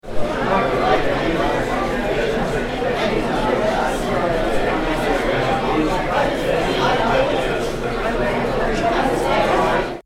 Restaurant Chatter 02
Restaurant_chatter_02.mp3